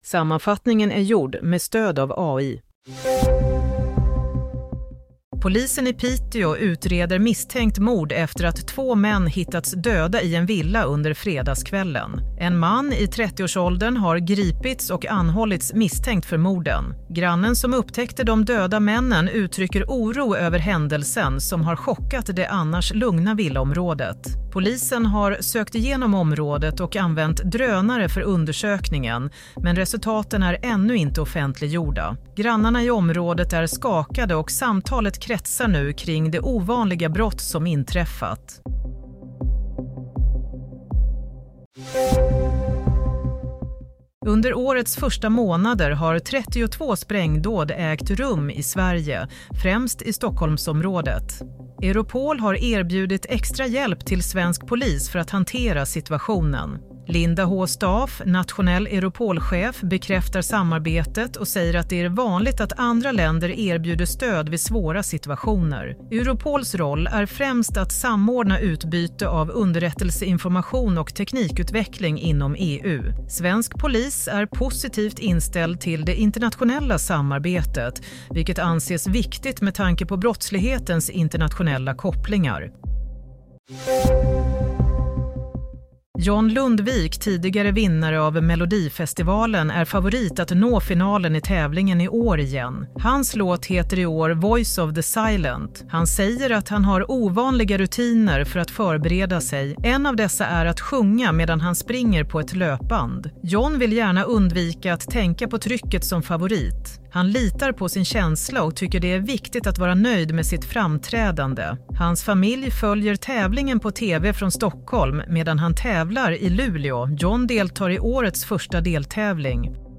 Nyhetssammanfattning – 1 februari 16:00
Sammanfattningen av följande nyheter är gjord med stöd av AI. – Misstänkt mord: Två män hittade döda i villa – Efter sprängvågen – nu erbjuder Europol extra hjälp – John Lundvik om favoritpressen – och udda rutinen inför Melodifestivalen